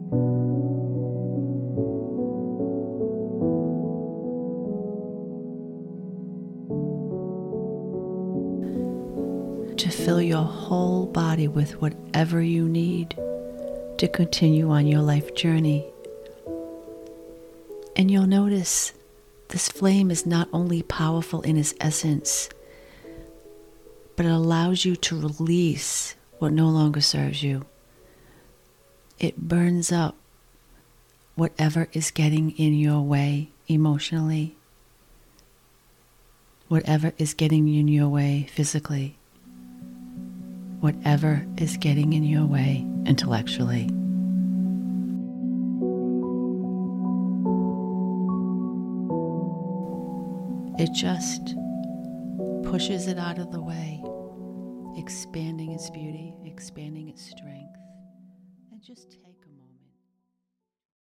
Here are a few clips…some have music in the background, some do not.